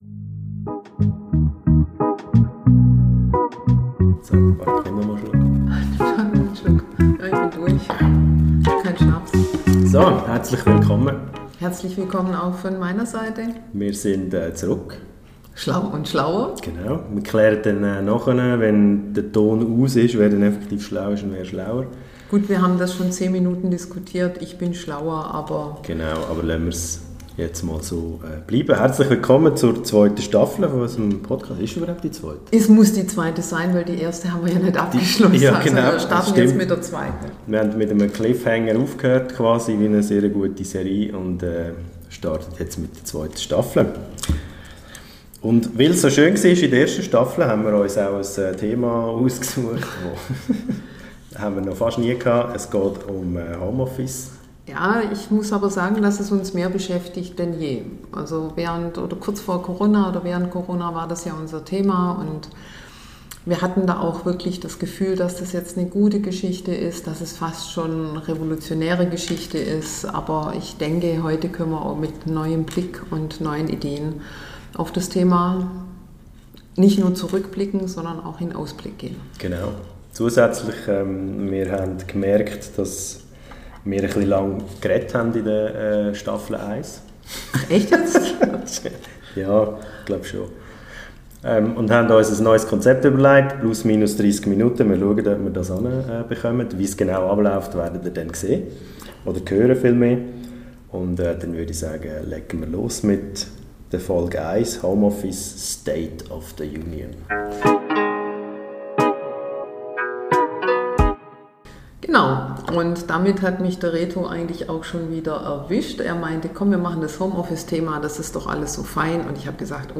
(Und sorry für die Audioqualität.